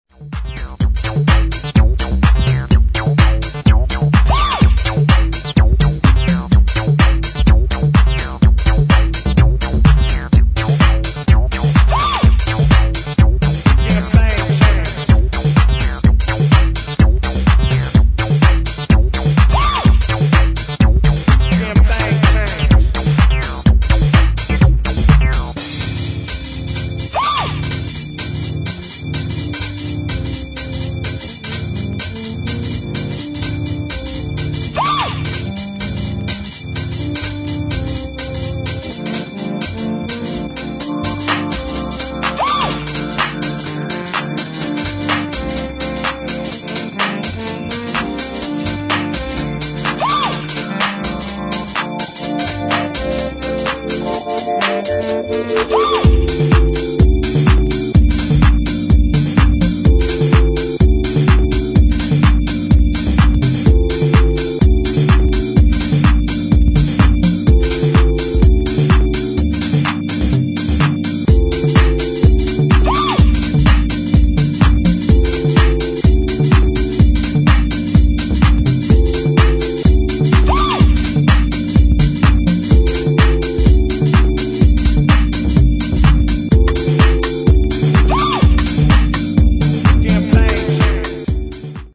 [ HOUSE / DISCO ]